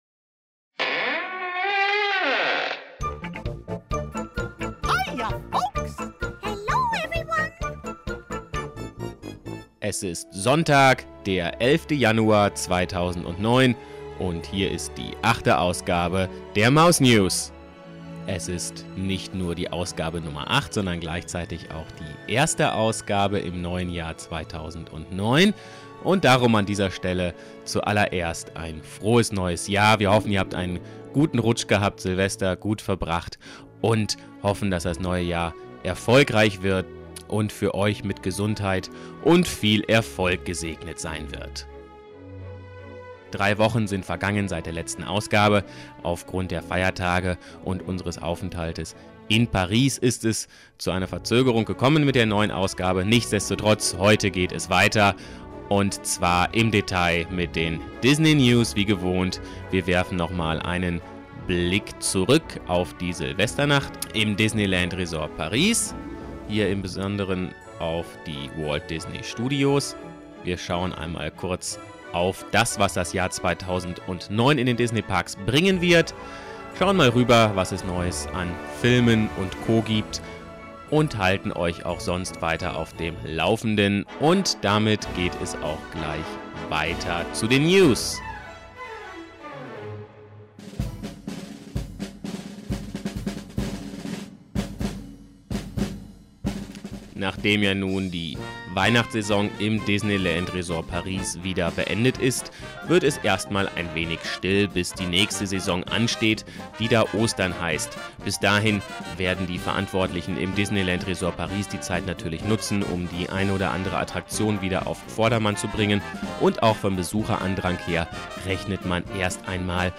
– Ausblick Entschuldigt die Qualität – technische Probleme 🙁